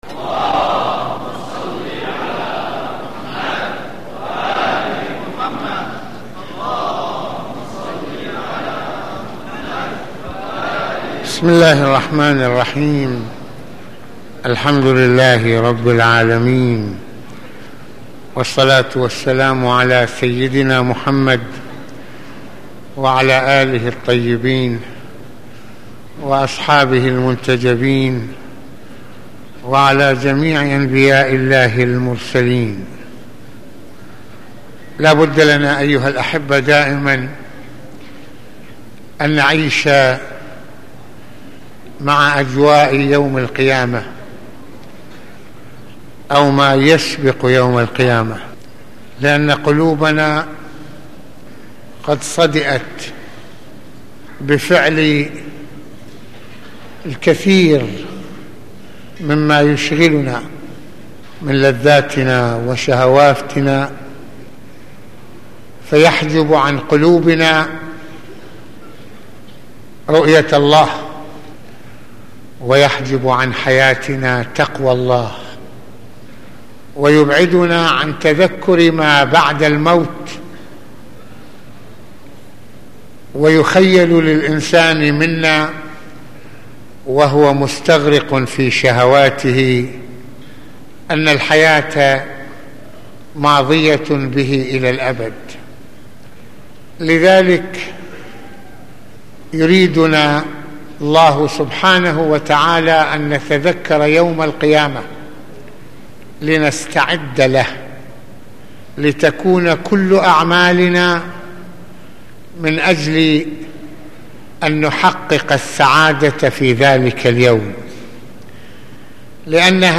ملفات وروابط - المناسبة : موعظة ليلة الجمعة المكان : مسجد الإمامين الحسنين (ع) المدة : 24د | 59ث المواضيع : مشاهد من يوم القيامة - نظرة الى اصحاب الجنة في نعيمهم - الذين لا يؤمنون بالاخرة كيف يفكرون ؟